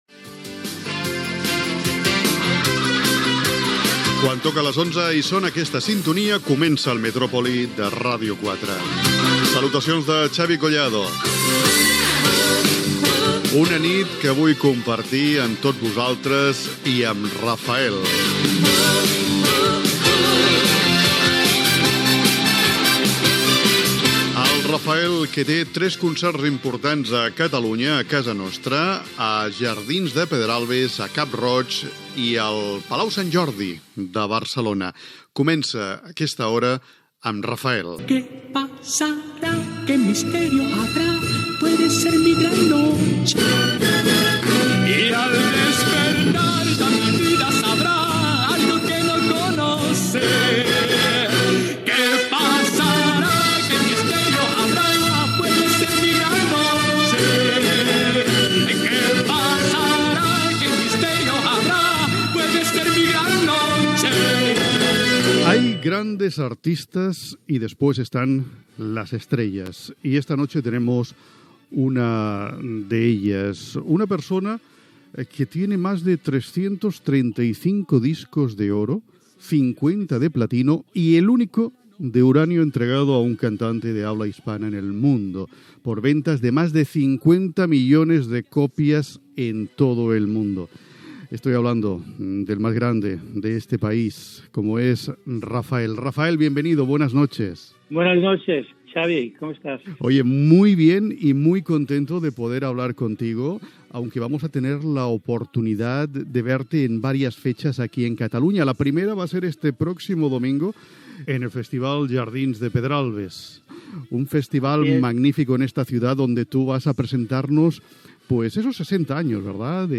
Sintonia, presentació, tema musical i entrevista al cantant Raphael (Miguel Rafael Martos Sánchez) que té tres actuacions programades a Catalunya i ha editat un nou disc. Valora la difusió de les seves cançons a la ràdio mexicana
Entreteniment